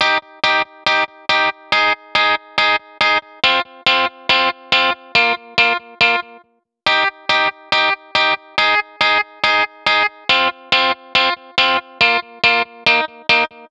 VTDS2 Song Kit 09 Female Play Girl Guitar.wav